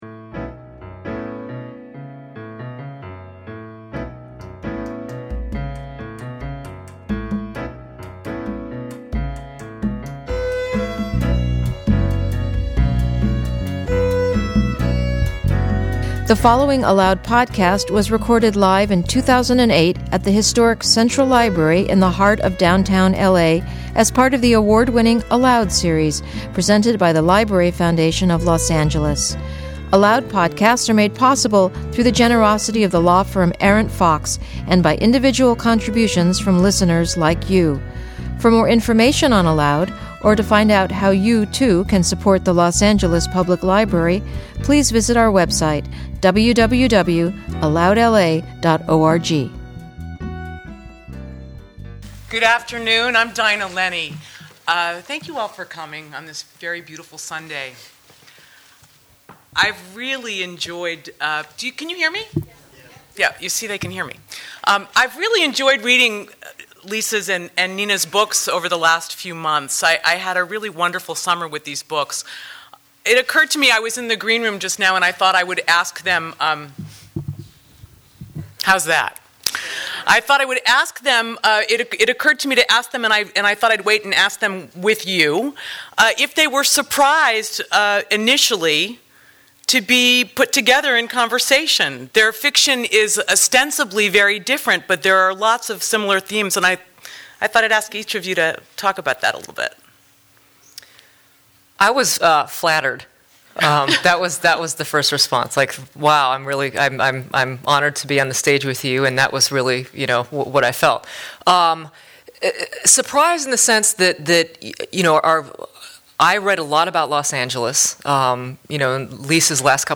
Forgotten Histories: Two Novelists in Conversation